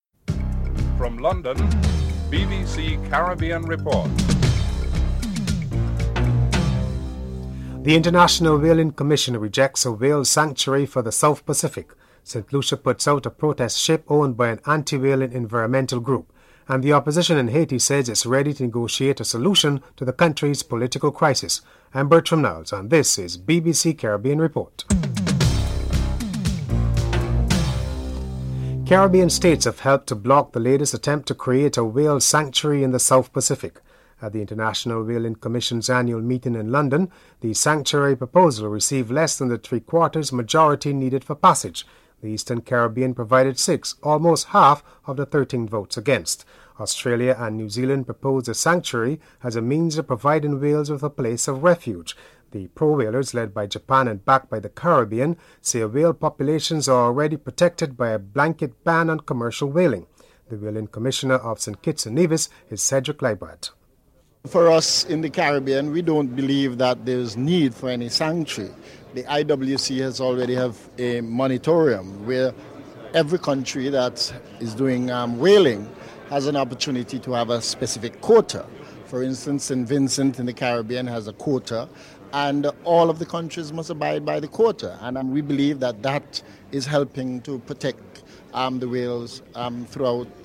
Dominica's Attorney General and Minister of Legal affairs David Bruney is interviewed (09:56-11:33)